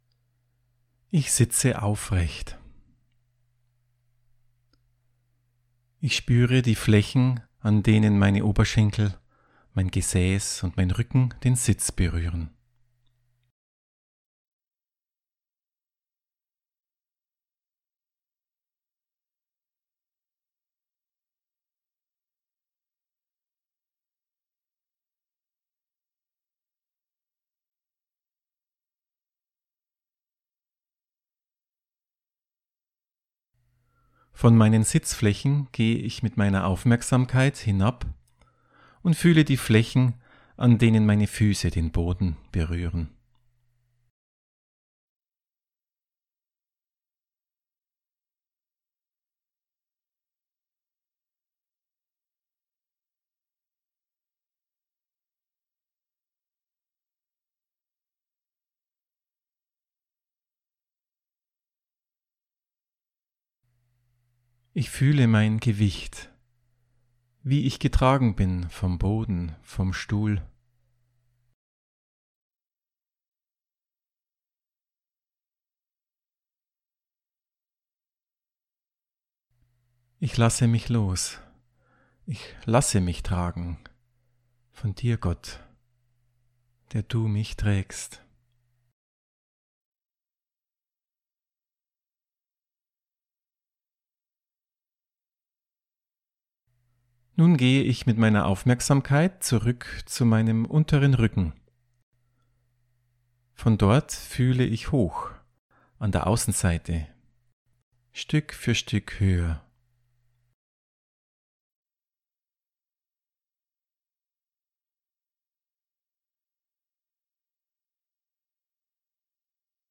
Audio-Anleitung in eine Freiraum-Übung / Körperwahrnehmungsübung aus dem Focusing.
Audio-Anleitung